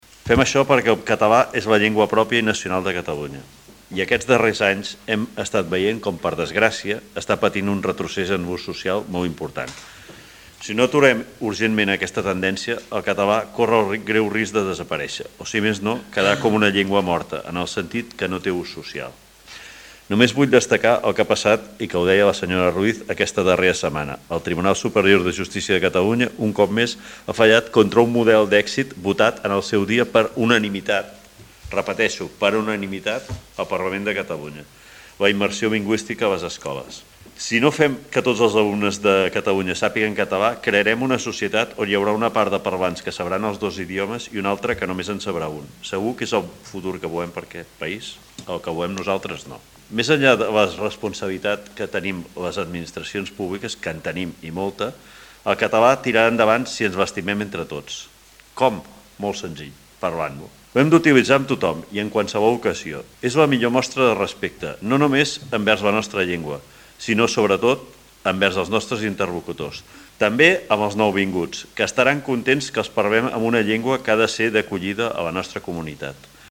Lluís Sagarra, regidor de Seguretat Ciutadana i Mobilitat